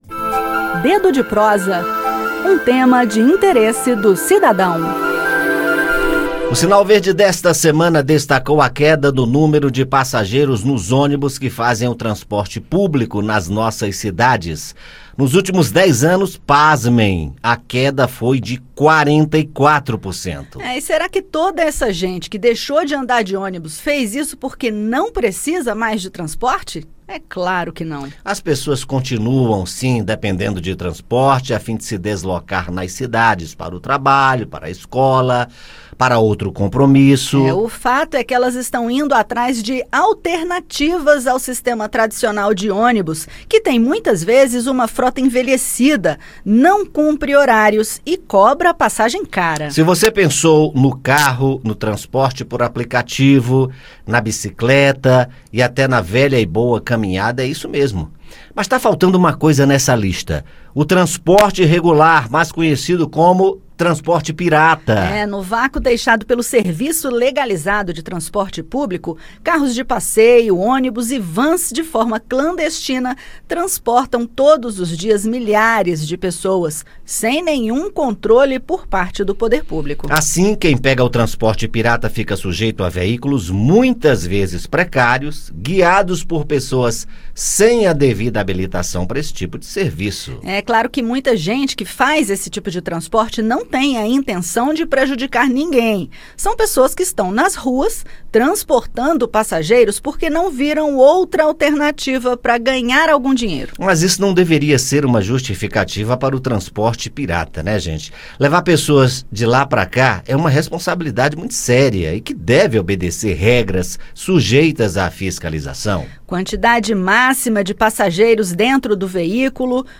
Um dos motivos de ainda existir esse tipo de transporte clandestino é a deficiência e a falta de qualidade do serviço de transporte público. Ouça detalhes no bate-papo.